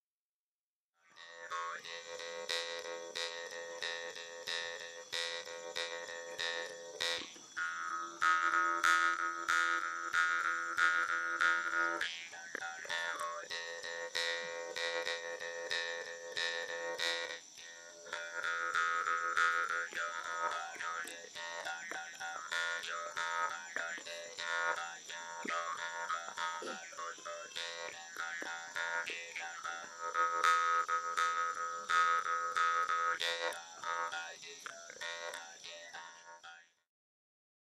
bamboo mouth harp solo courting tune in which the player says he's lonely and the mosquitoes are biting him 590KB
Track 28 Akha mouth harp.mp3